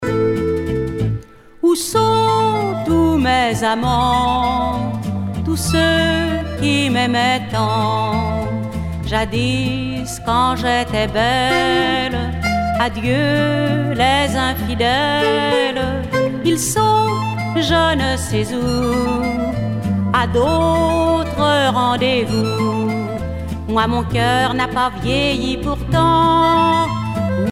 danse : valse musette
Pièce musicale éditée